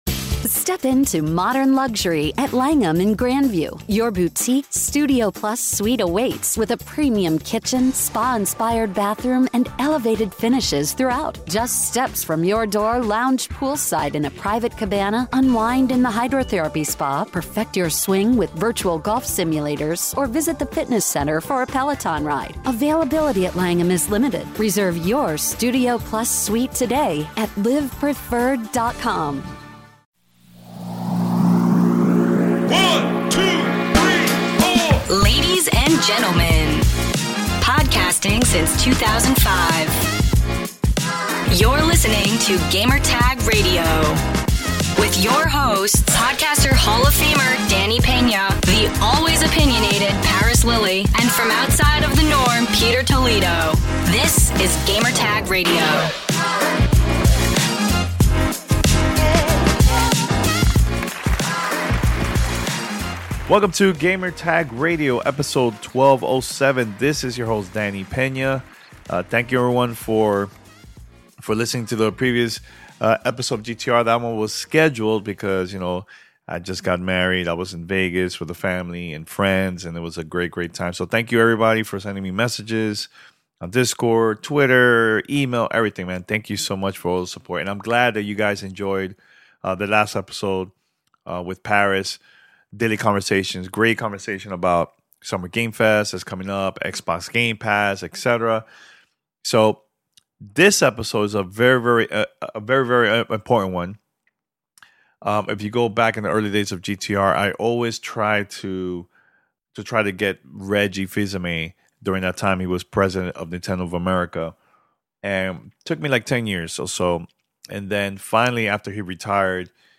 Live from GamesBeat Summit 2022